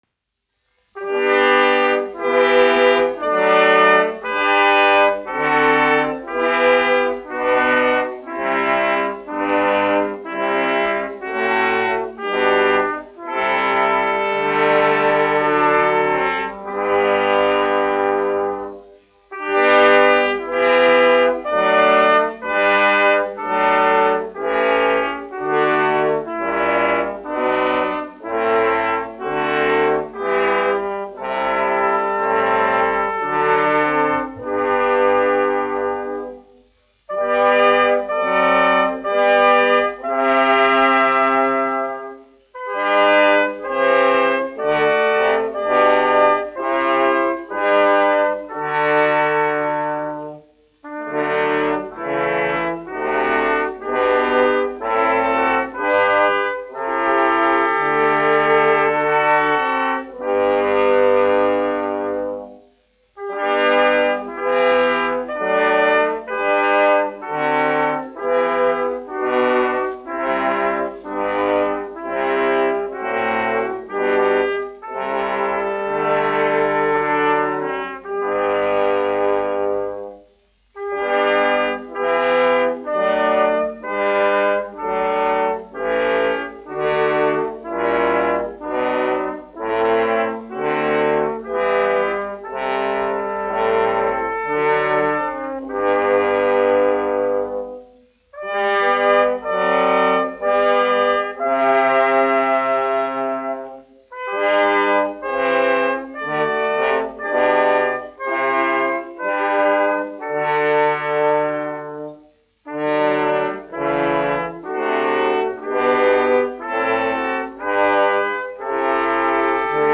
Latvijas Nacionālā opera Pūtēju kvartets, izpildītājs
1 skpl. : analogs, 78 apgr/min, mono ; 25 cm
Korāļi
Pūšaminstrumentu kvarteti
Skaņuplate